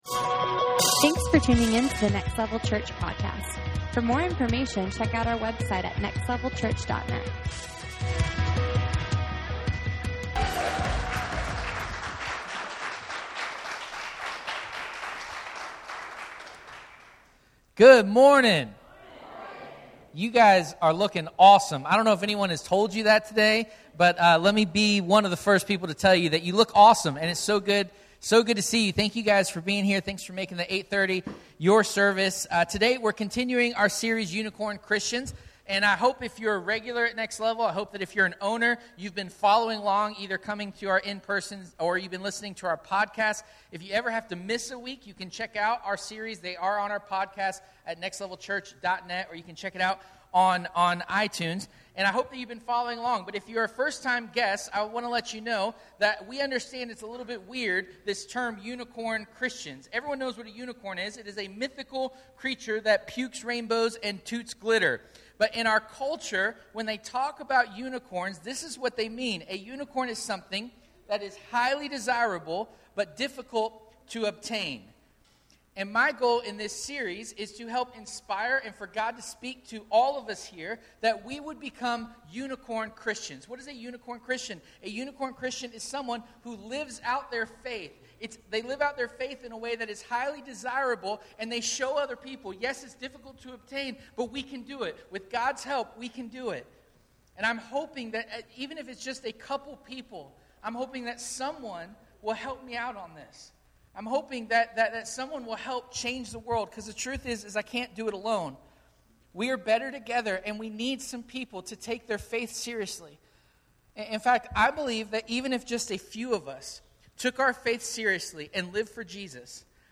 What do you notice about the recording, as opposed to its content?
Unicorn Christians Service Type: Sunday Morning Watch A unicorn is a mythical creature.